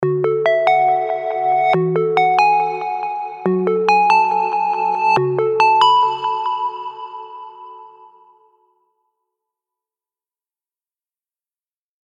It’s ethereal and quite epic in nature.
In this case the changes were really subtle and organic.
A few simple reverses are put in place
The reverse sections are added